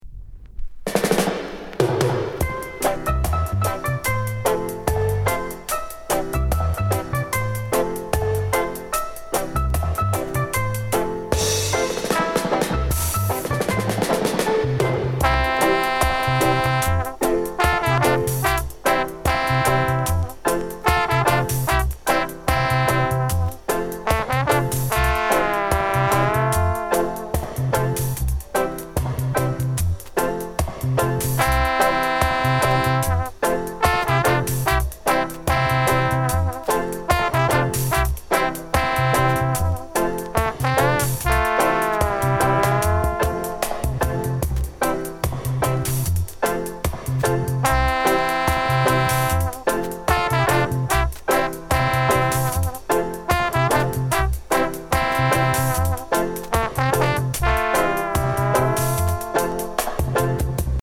HORN INST